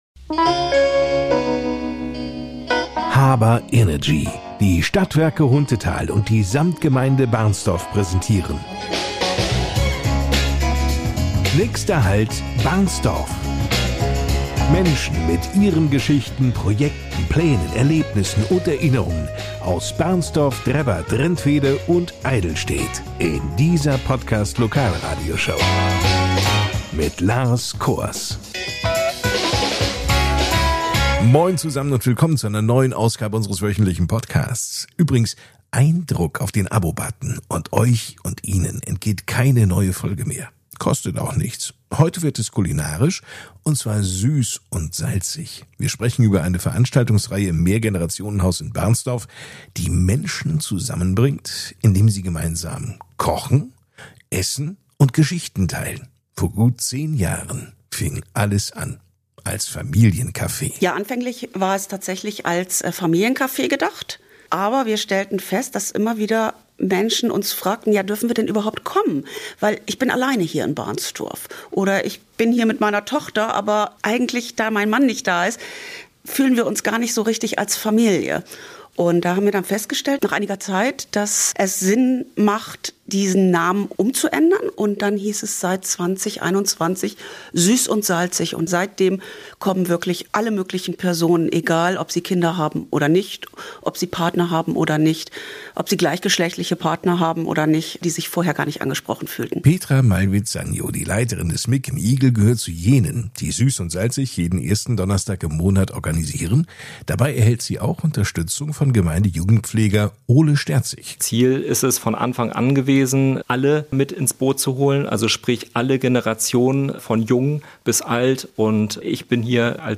Die Podcast-Lokalradioshow